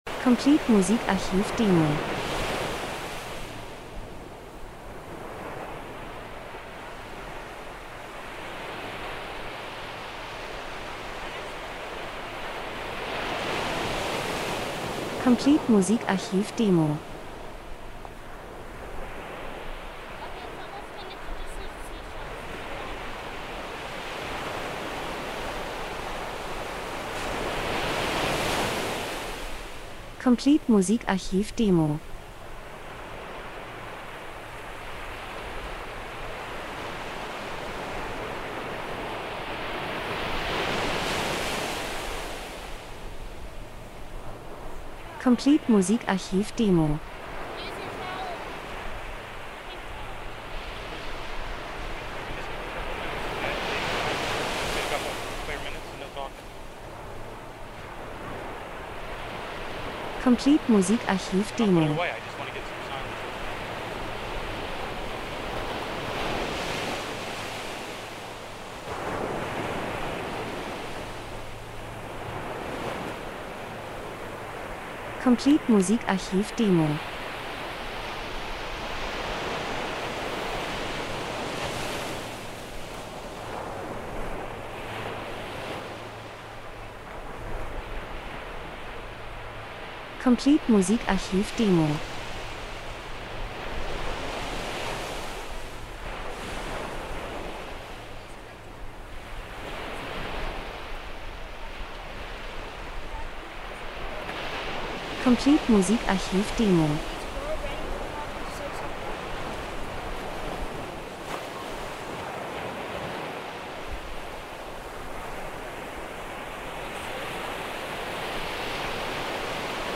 Sommer -Geräusche Soundeffekt Natur Meer Wellen Strand 02:49